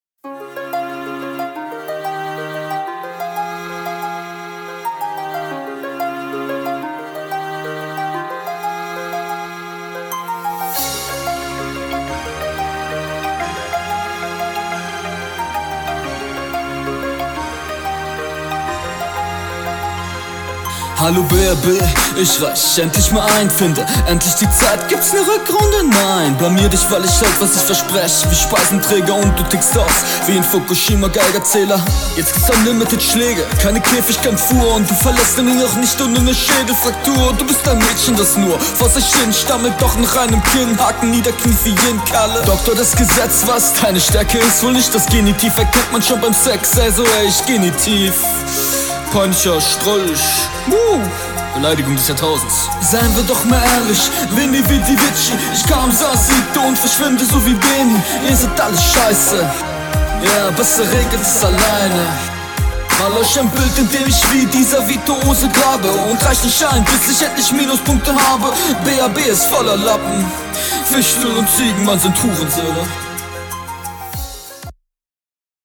➨ Alles sehr taktsicher.
Flow: Du bist auf dem Takt bist aber teilweise unverständlich.